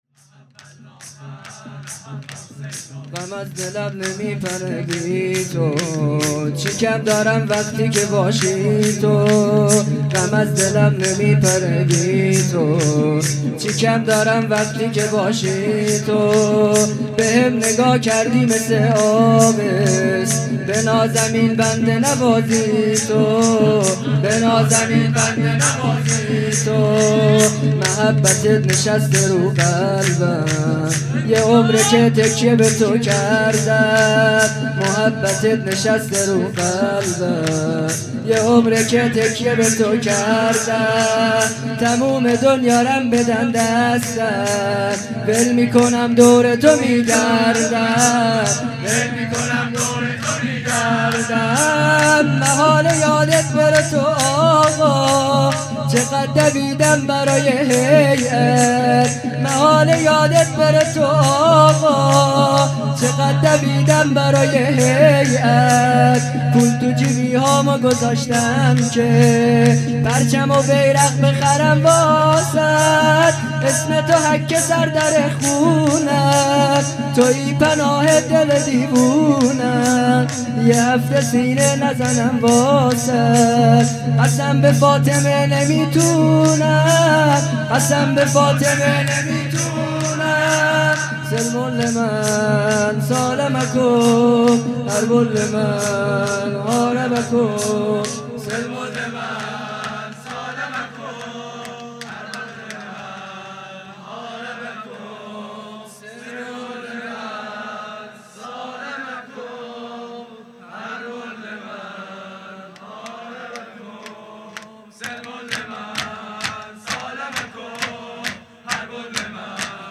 خیمه گاه - هیئت بچه های فاطمه (س) - شور | غم از دلم نمی پره بی تو، چی کم دارم وقتی که باشی تو
جلسه هفتگی